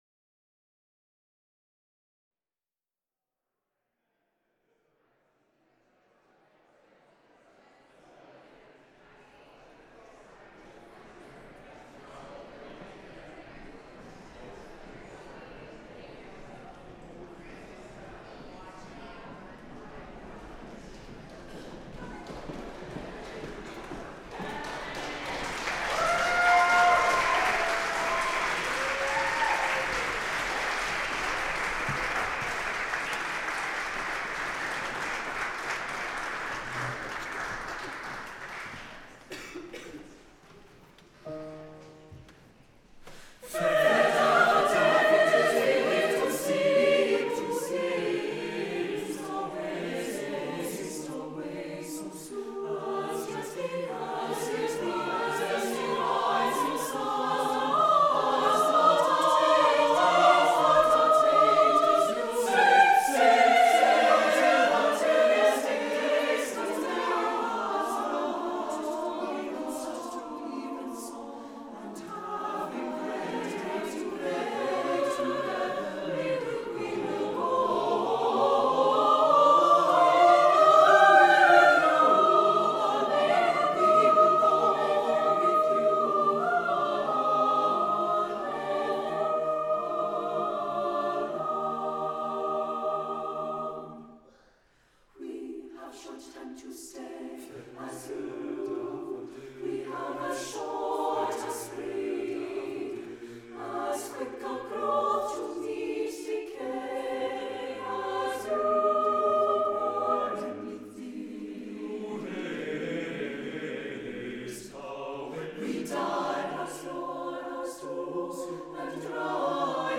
Gonzaga Chamber Chorus May1 2016.mp3